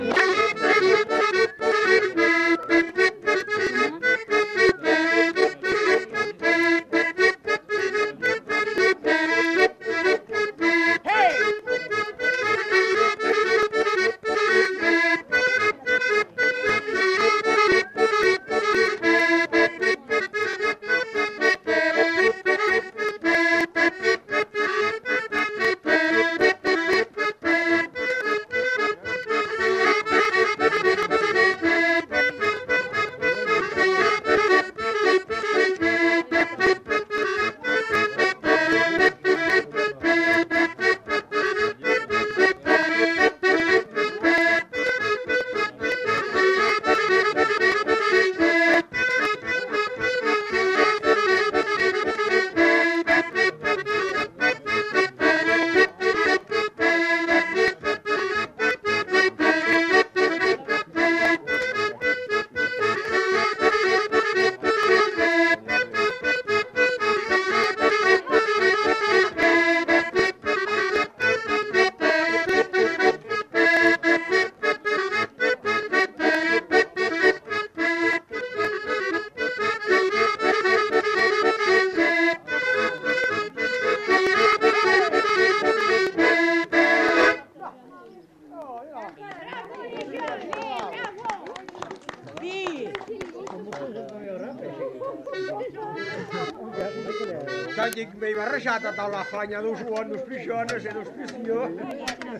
Valse